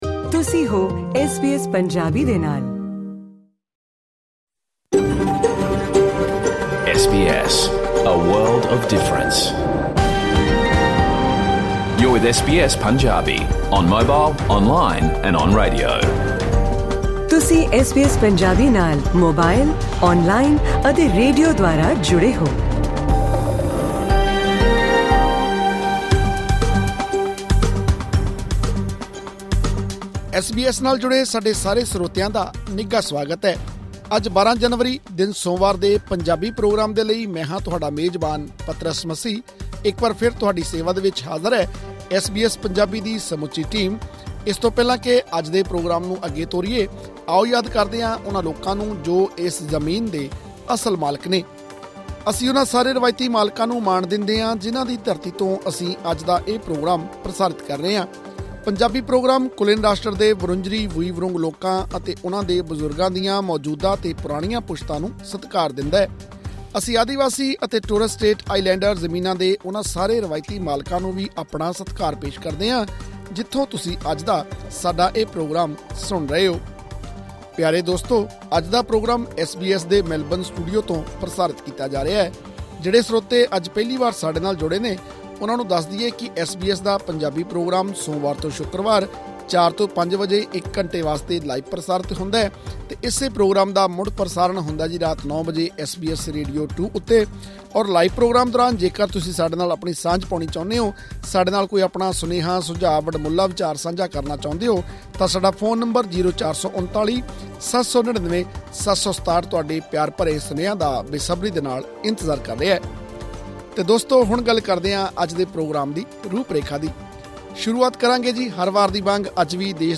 ਐਸ ਬੀ ਐਸ ਪੰਜਾਬੀ ਦਾ ਰੇਡੀਓ ਪ੍ਰੋਗਰਾਮ ਸੋਮਵਾਰ ਤੋਂ ਸ਼ੁੱਕਰਵਾਰ ਸ਼ਾਮ 4 ਵਜੇ ਤੋਂ 5 ਵਜੇ ਤੱਕ ਲਾਈਵ ਪ੍ਰਸਾਰਿਤ ਹੁੰਦਾ ਹੈ।